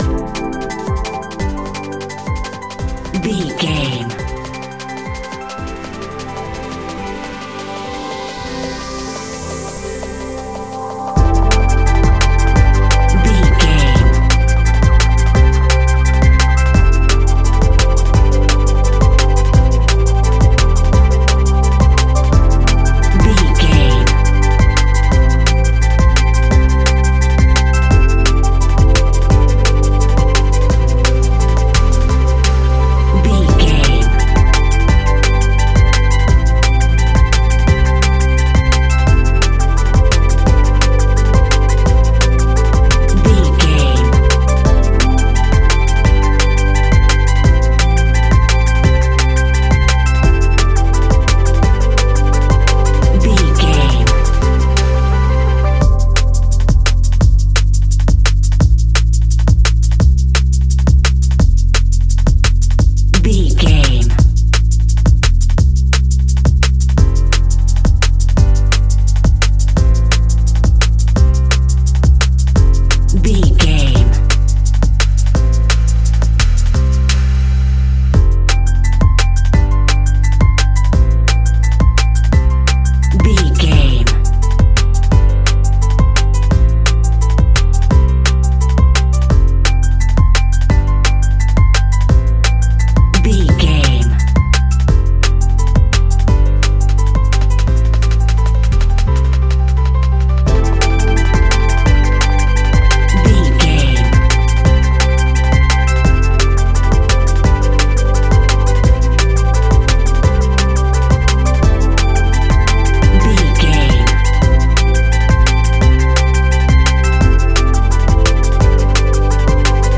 Ionian/Major
electronic
dance
techno
trance
synths
synthwave
instrumentals